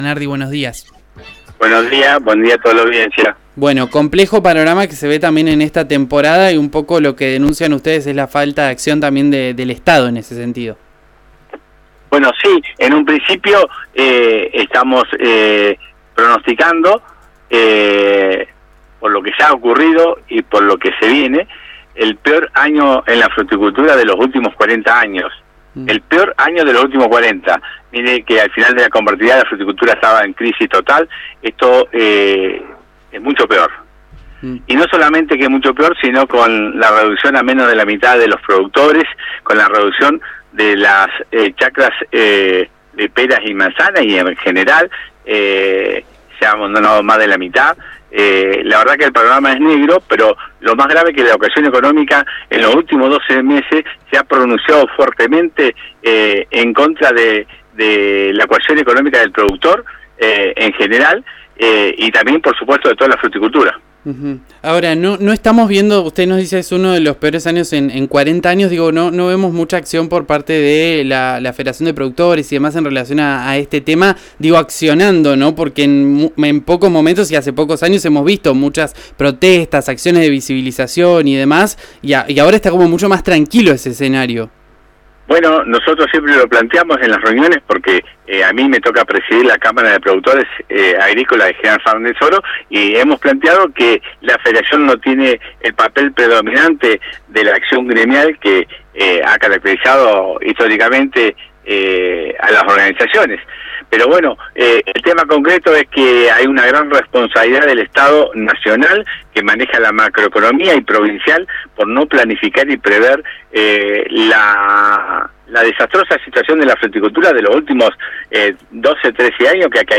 en diálogo con RÍO NEGRO RADIO.